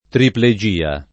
triplegia [ triple J& a ]